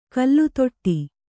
♪ kallutoṭṭi